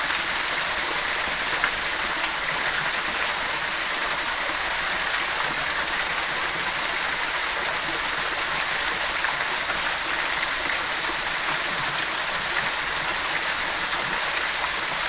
Heavy_Rain_Loop.ogg